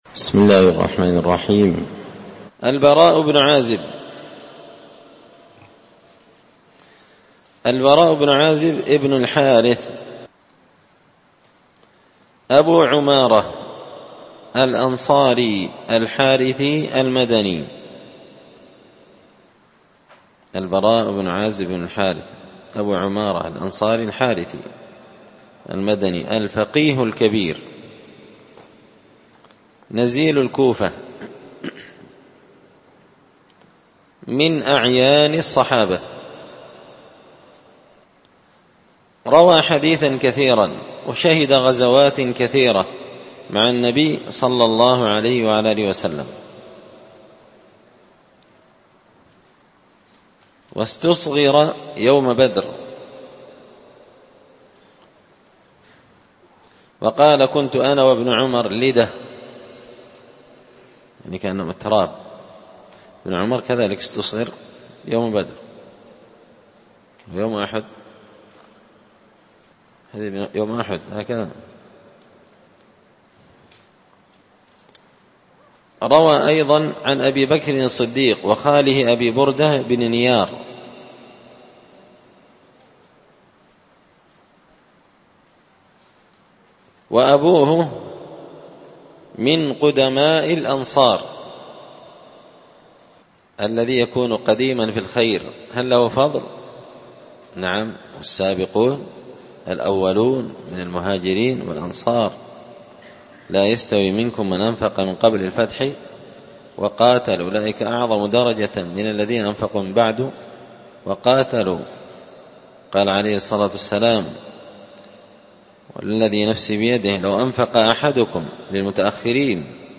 قراءة تراجم من تهذيب سير أعلام النبلاء
دار الحديث بمسجد الفرقان ـ قشن ـ المهرة ـ اليمن